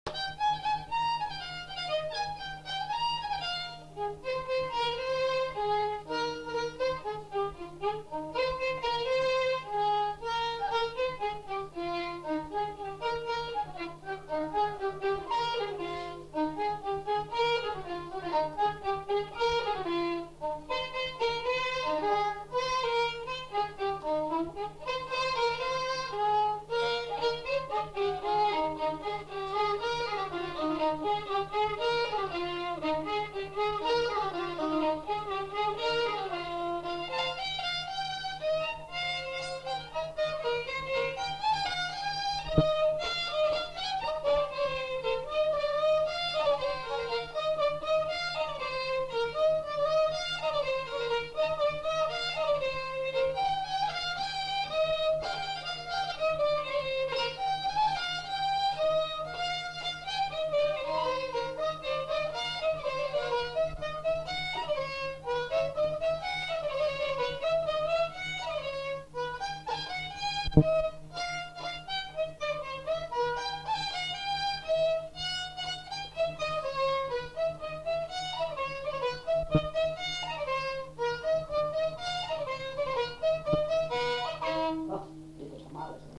Aire culturelle : Lugues
Lieu : Saint-Michel-de-Castelnau
Genre : morceau instrumental
Instrument de musique : violon
Danse : rondeau
Notes consultables : 2 violons. Accélération de la bande.